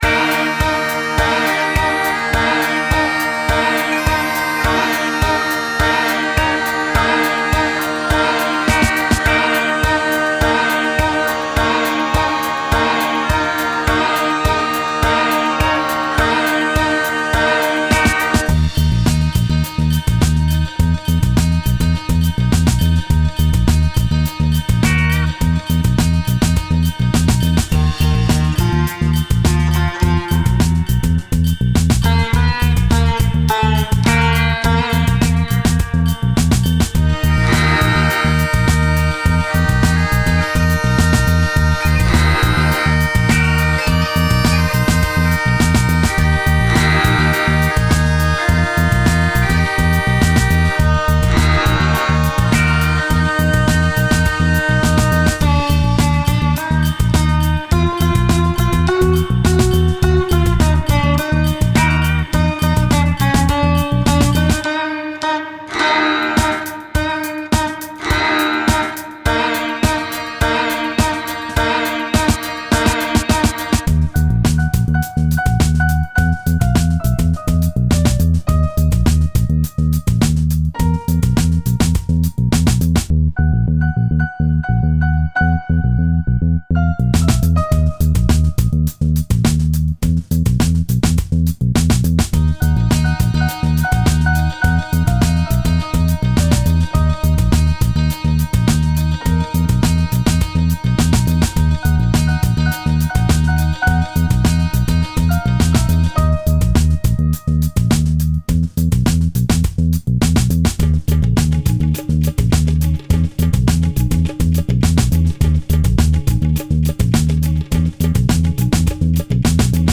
sitar
piano, programmation cornemuse